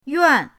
yuan4.mp3